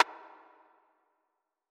Metro Rim(w_reverb).wav